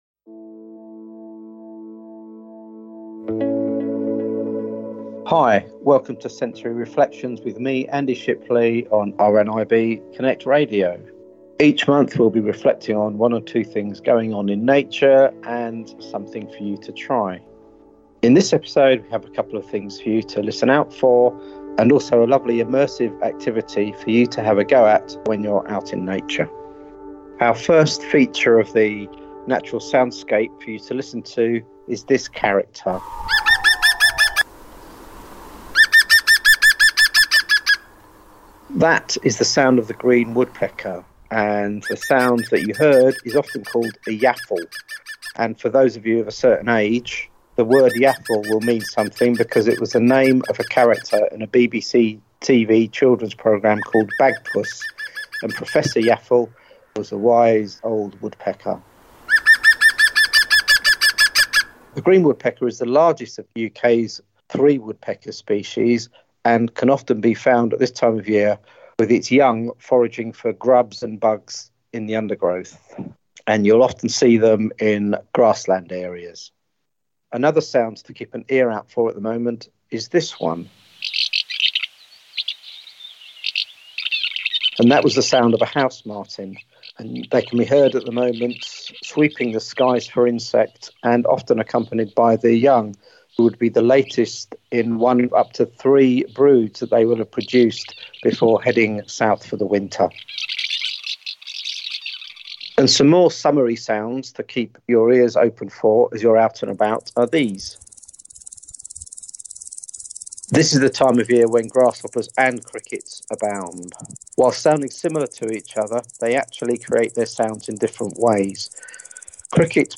Sound credits: ' HOUSE MARTIN CALLS SOUND ' via Quick Sounds, 'Grasshopper' via pixabay, ' Green Woodpecker ' via british-birdsongs.